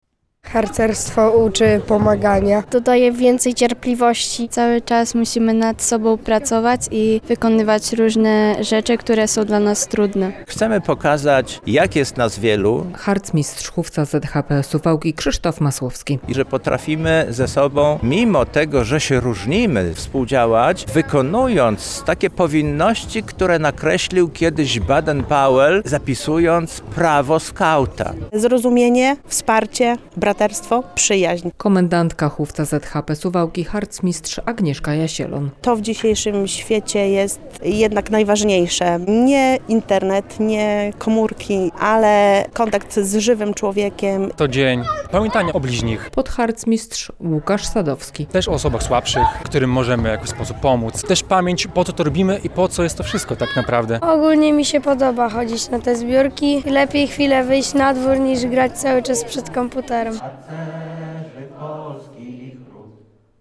Dzień Myśli Braterskiej w Suwałkach - relacja